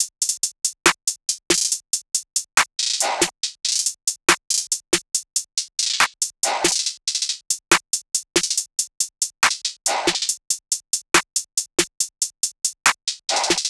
SOUTHSIDE_beat_loop_butter_top_01_140.wav